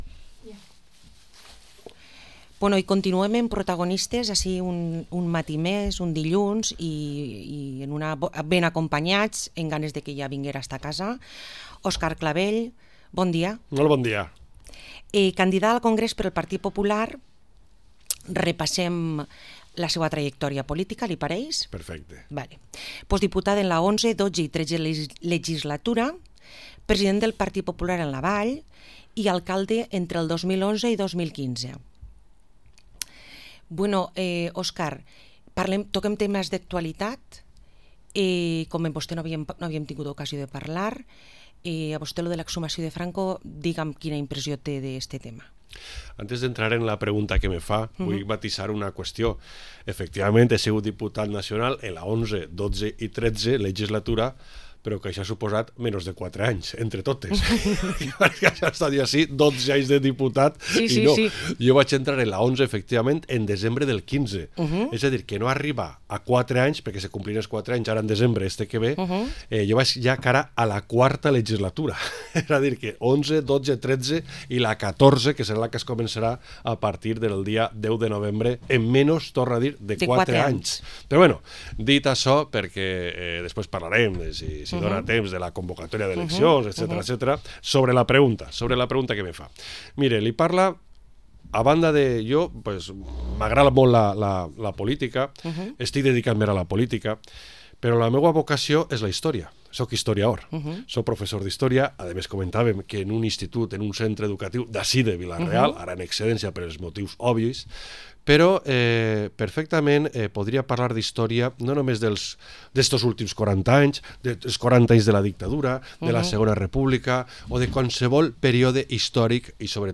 Entrevista Óscar Clavell, cabeza lista del PP de Castellón al Congreso. 28 de octubre del 2019